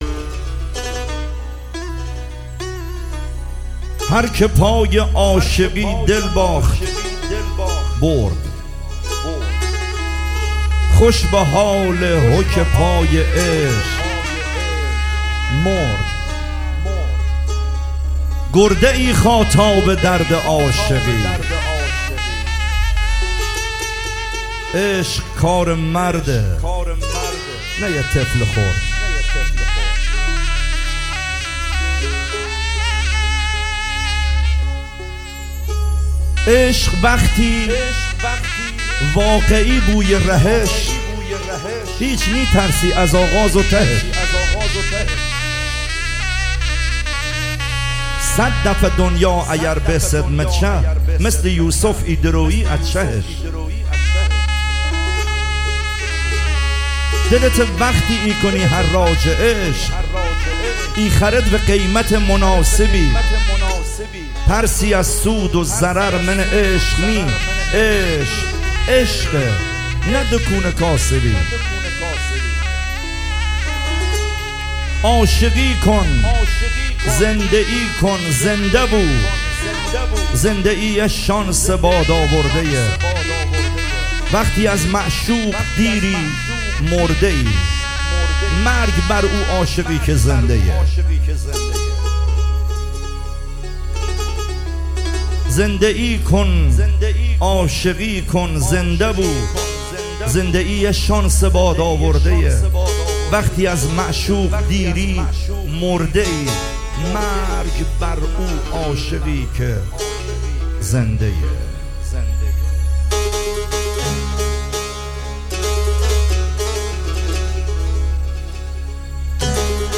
عاشقانه محلی لری